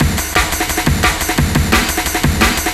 cw_amen01_175.wav